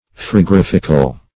Frigorifical \Frig"o*rif`ic*al\a.